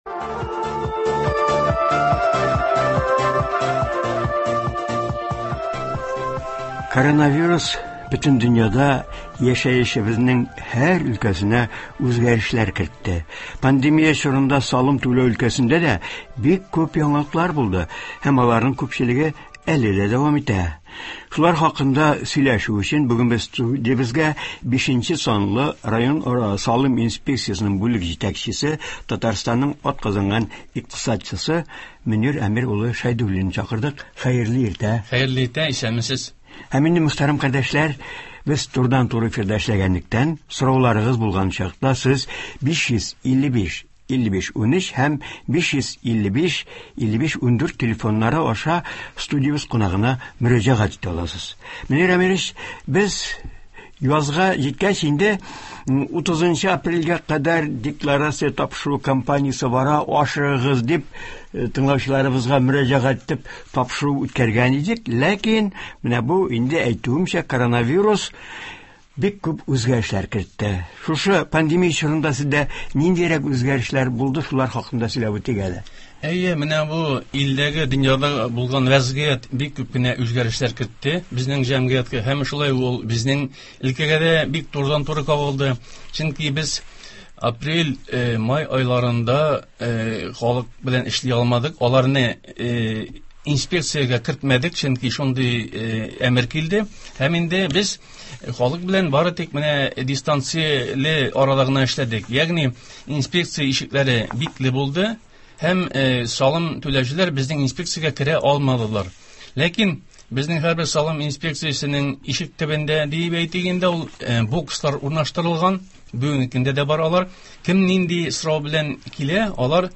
Туры эфир. 22 июнь.